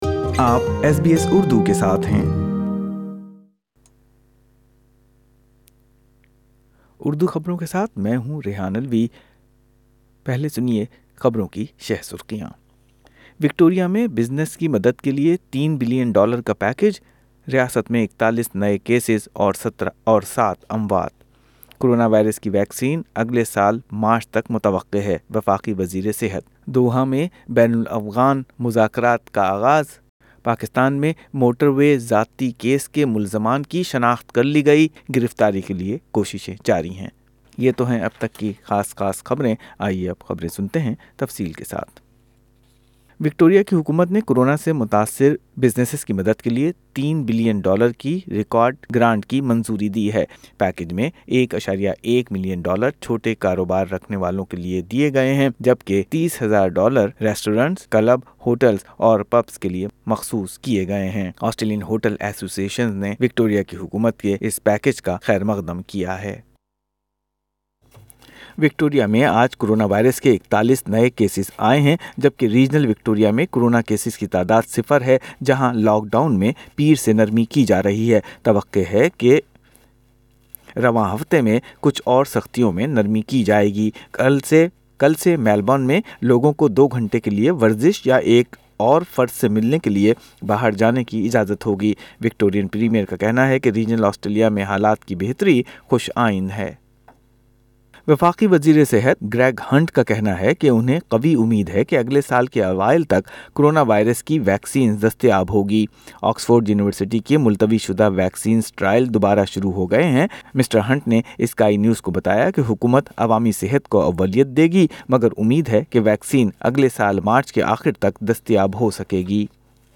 اردو خبریں اتوار 13 ستمبر 2020